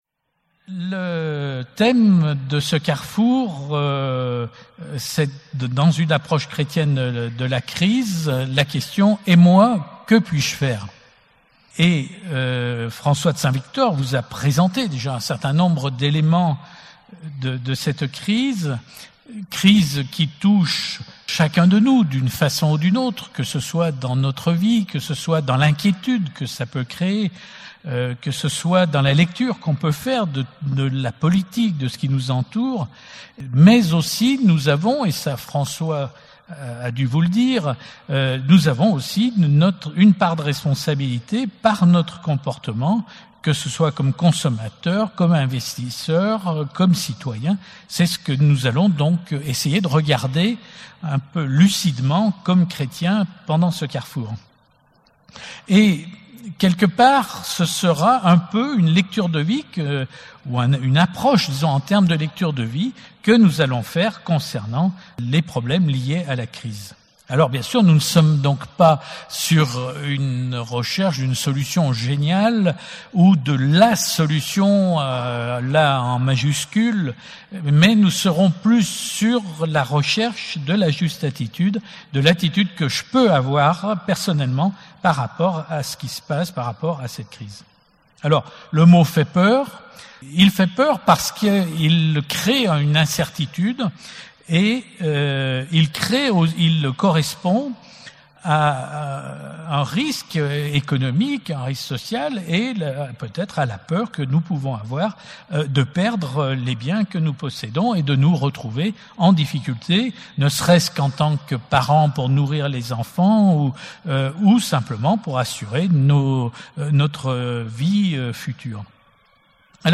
Enseignement
Session 25 - 35 ans : L'Amour me presse (14 au 18 juillet 2012)